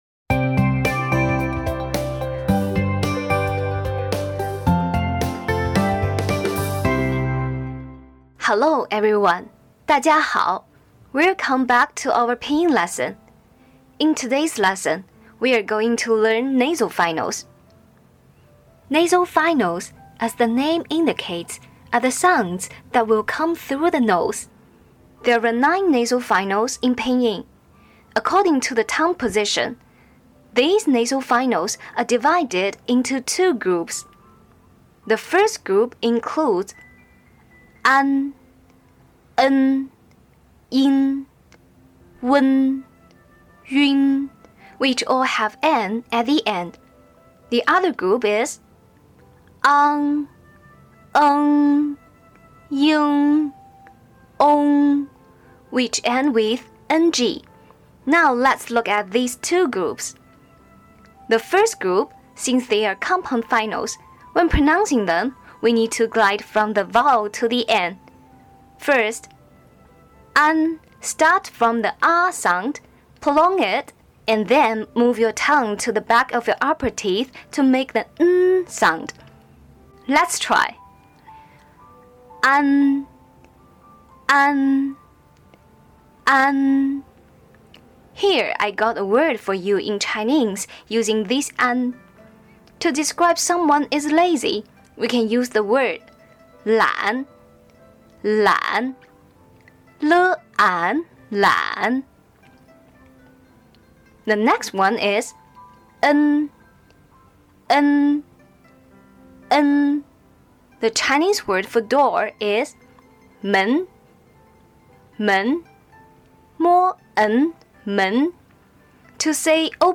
In this Pinyin lesson, we'll learn how to pronounce the 9 nasal finals: an, en, in, un, ün, ang, eng, ing, ong.
Practice and repeat with us until you become familiar with these nasal finals.